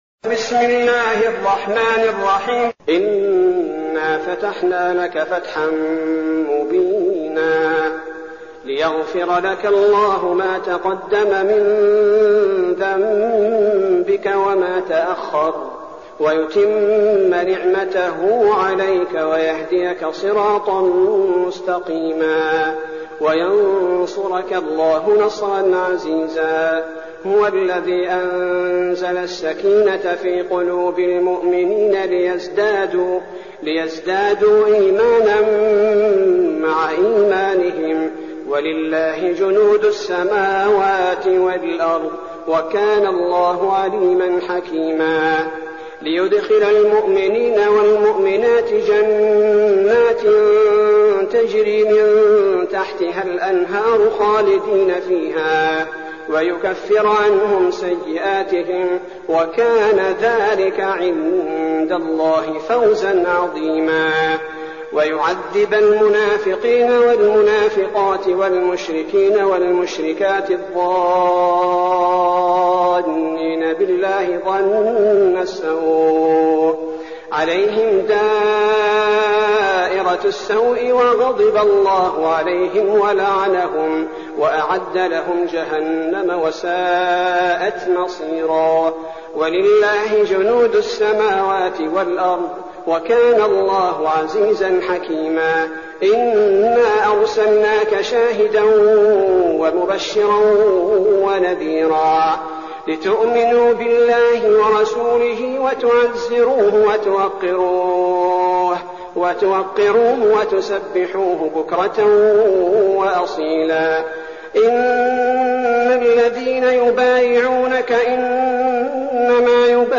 المكان: المسجد النبوي الشيخ: فضيلة الشيخ عبدالباري الثبيتي فضيلة الشيخ عبدالباري الثبيتي الفتح